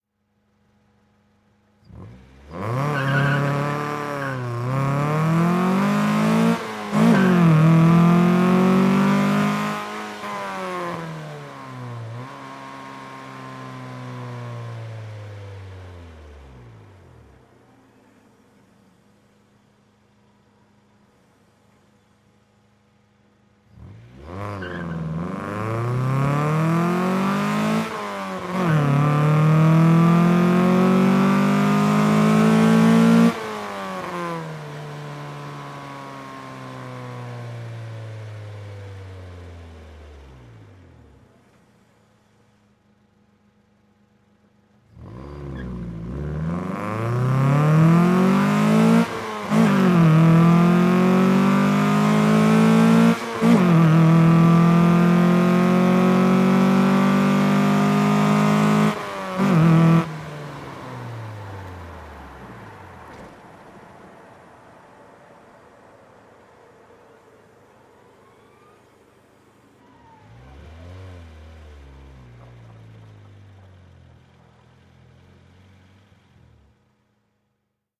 Integra onboard - engine, fast accelerate & coast bursts